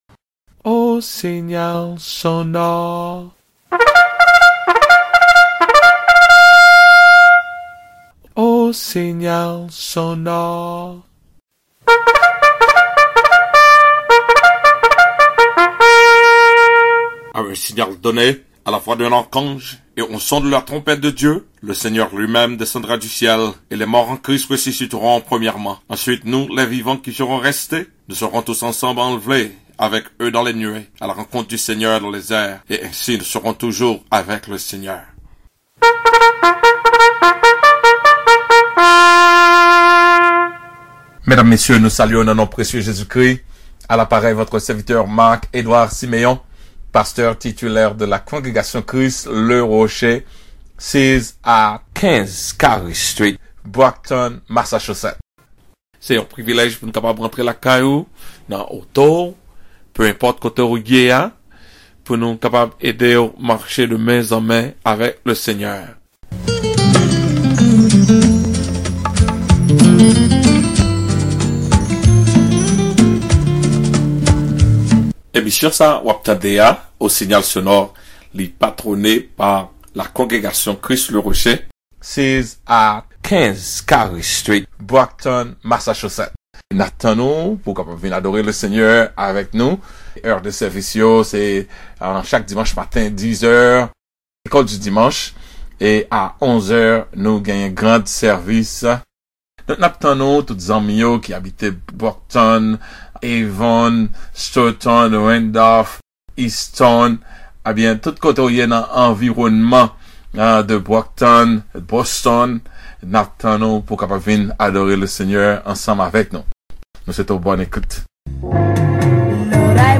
L AMOUR S ORIGINE AVEC DIEU CHRIST THE ROCK CONGREGATION SUNDAY SEPTEMBER 1ST 2019 Sermon